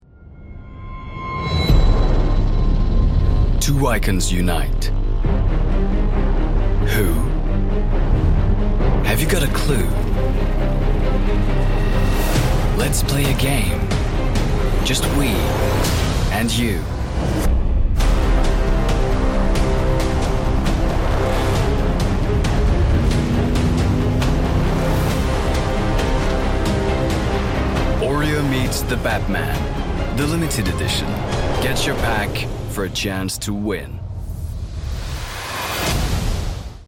MALE VOICE OVER DEMOS AND EXTRACTS
Commercial OREO